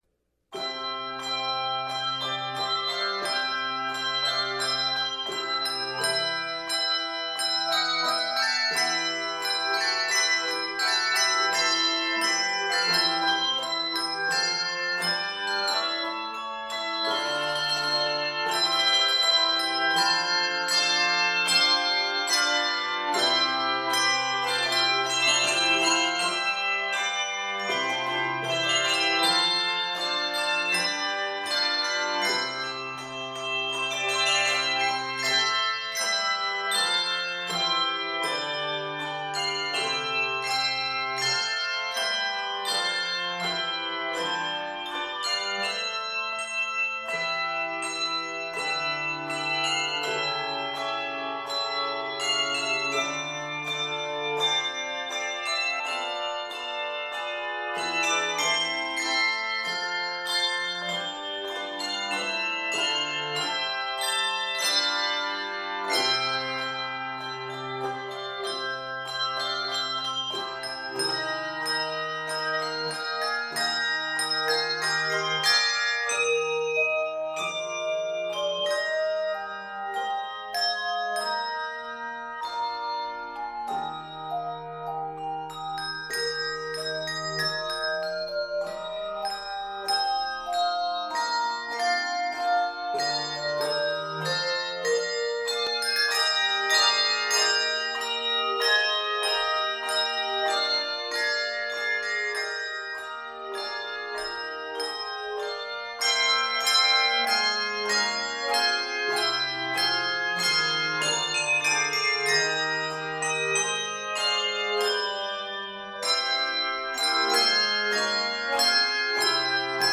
Keys: C Major and Eb Major.
Octaves: 3-5